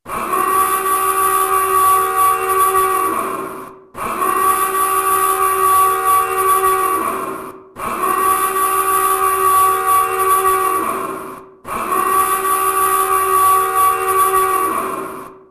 Звуки корабля, теплохода
Гул корабельной сирены